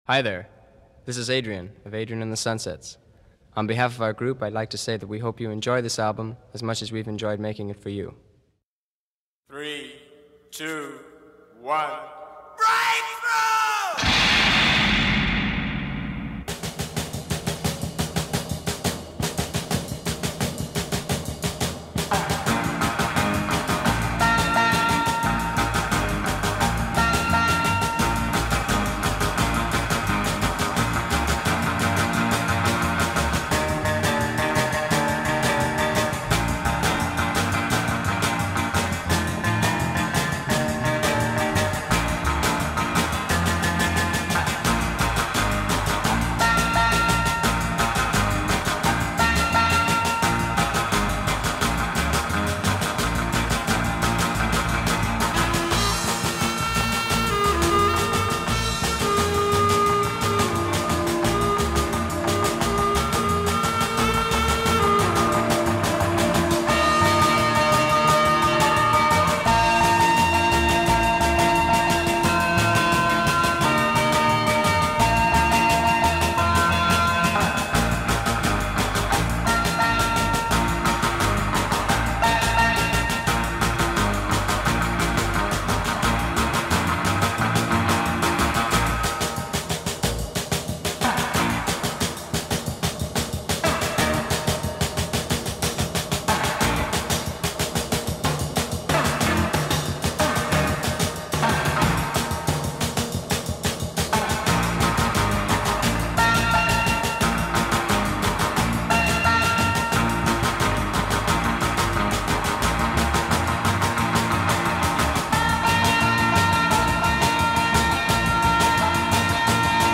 pure California Surf-Rock